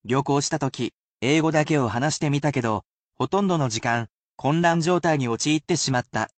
I am sure to repeat the main word(s) slowly, but I read the sentences at a natural pace, so do not worry about repeating after the sentences.
[casual speech]